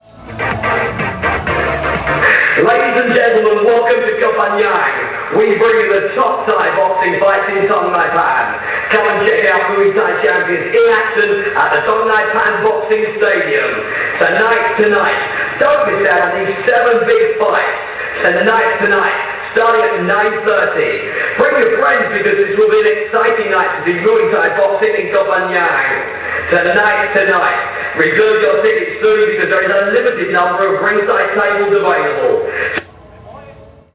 announcement_CIMG0653.mp3